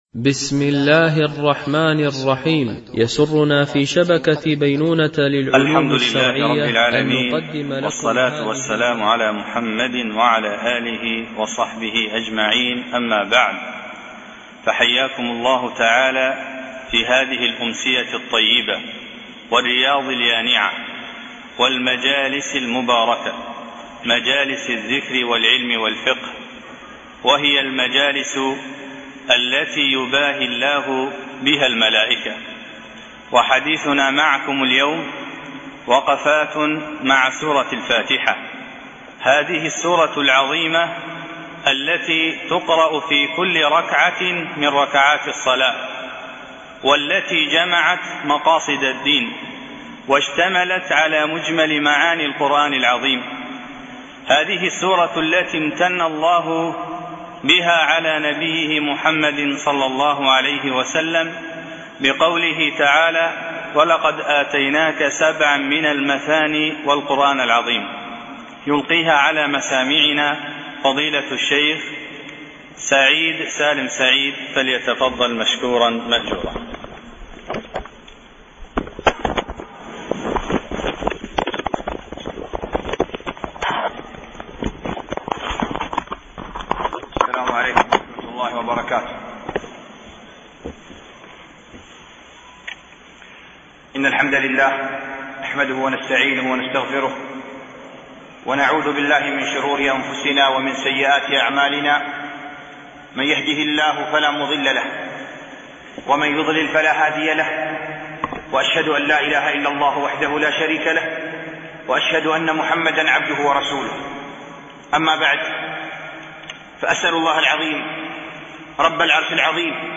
الاسباب و الأعمال التي يضاعف بها الله الثواب ـ الدرس الخامس و الأخير